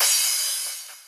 Cymbals Crash 05.ogg